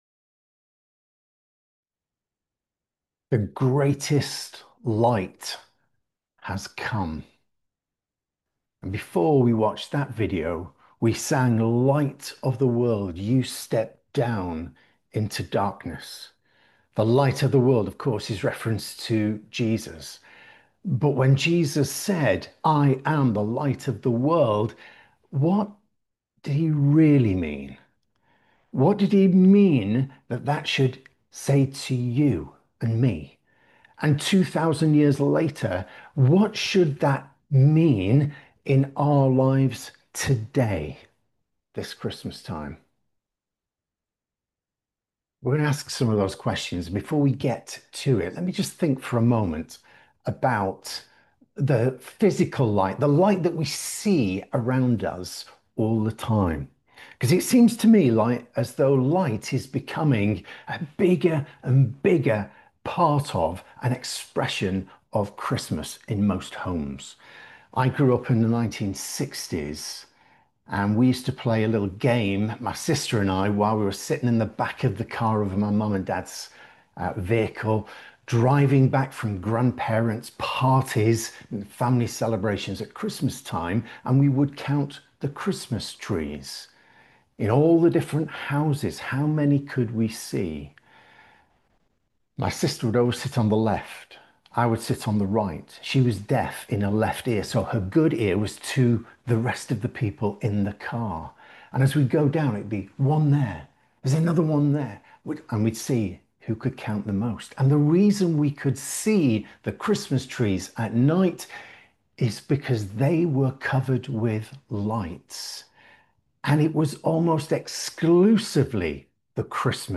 Carol service; the Light of the World is come